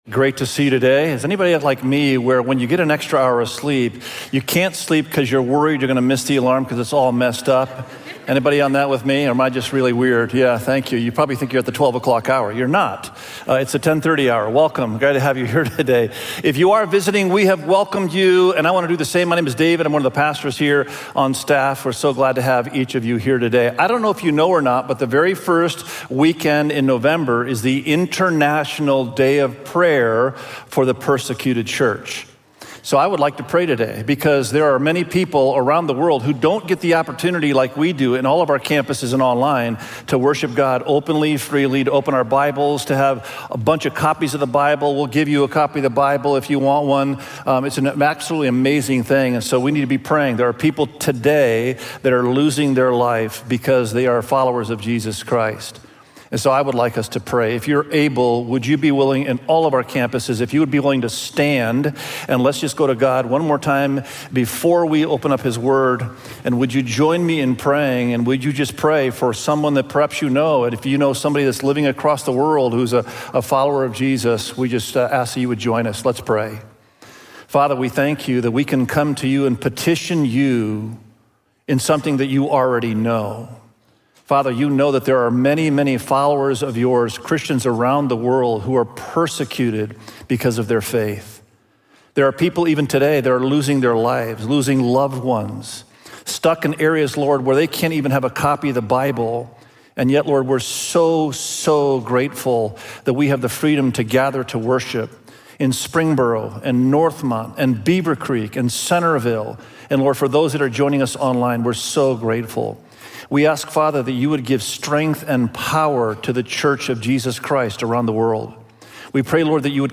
Living-with-Purpose-Through-the-Spirit_SERMON.mp3